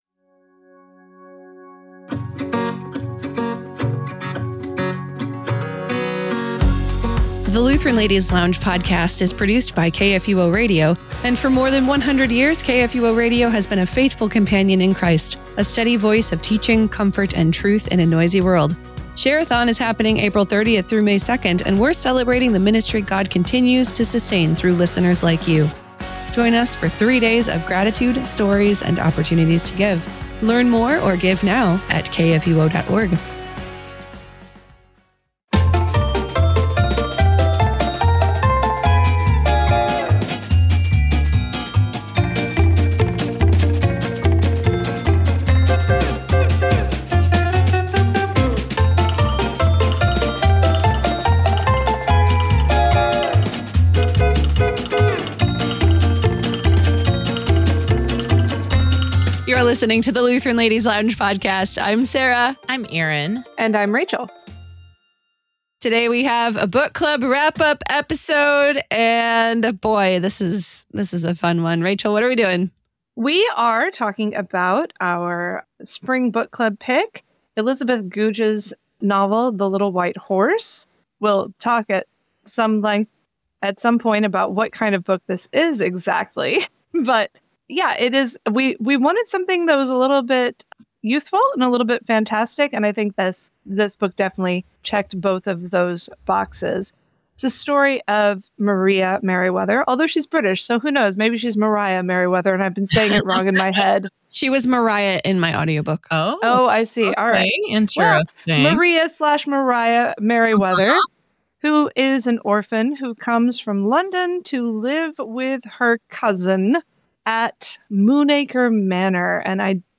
In this book club recap conversation